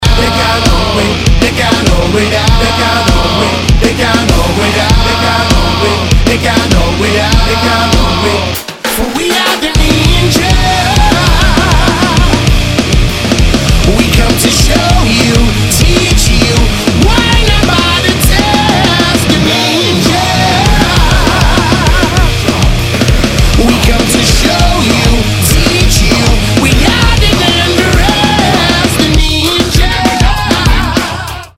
• Качество: 192, Stereo
Любителям тяжелого рока посвящается!
великолепную смесь из рока и регги.